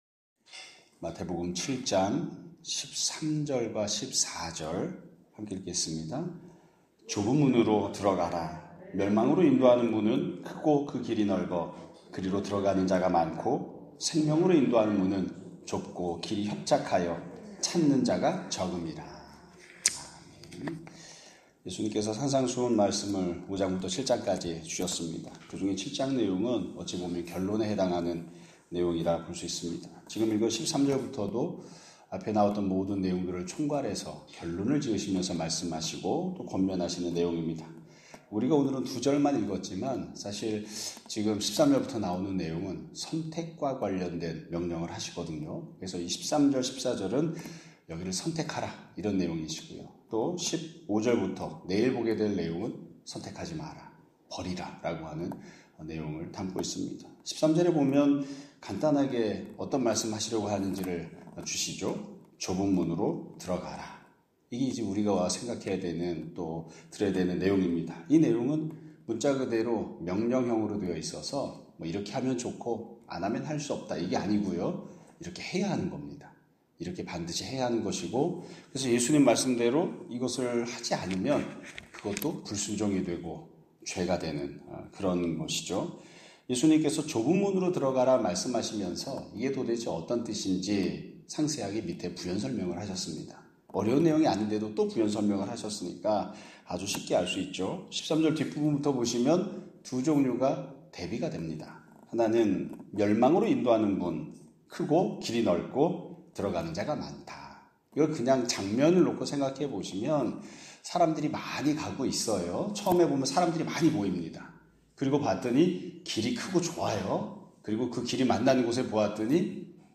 2025년 6월 30일(월요일) <아침예배> 설교입니다.